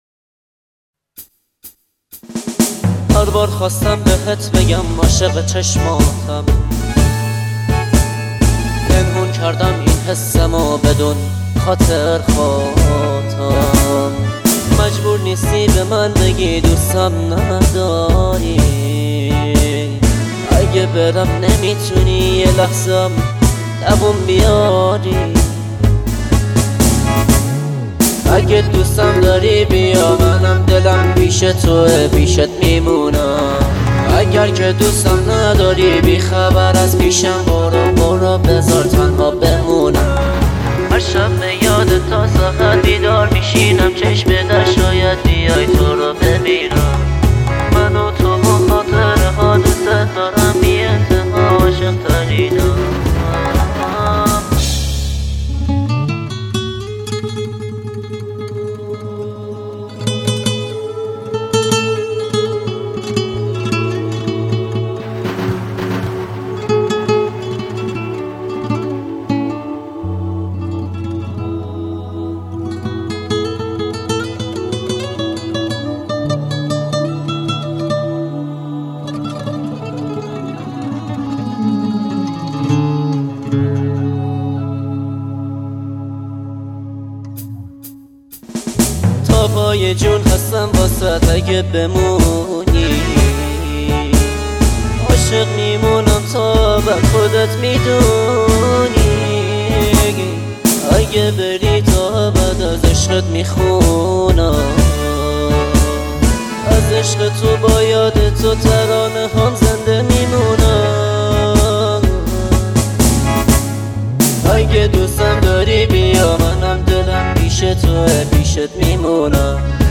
دسته بندی : پاپ